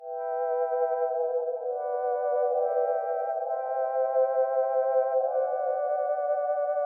Werid Synth 140
Tag: 140 bpm Chill Out Loops Synth Loops 1.15 MB wav Key : Unknown